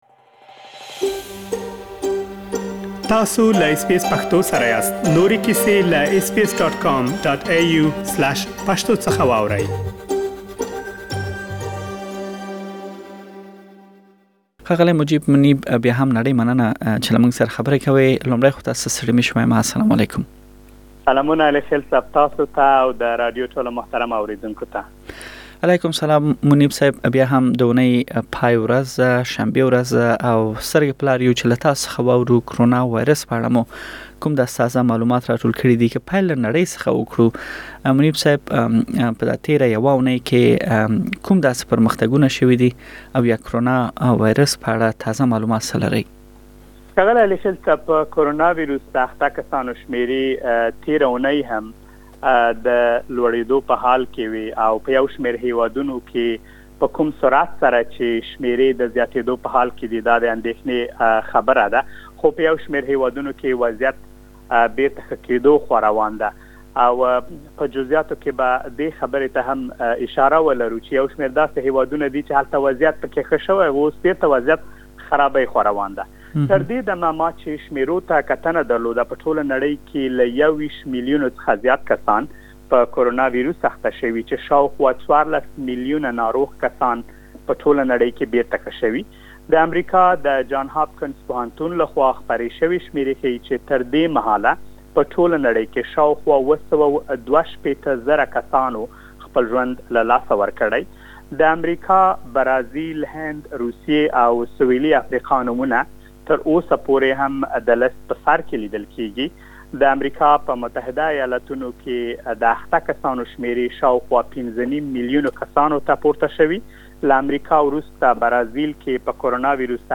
کرونا ويروس په اړه د اسټراليا، افغانستان او پاکستان په ګډون له نړۍ څخه مهم معلومات په رپوټ کې واورئ.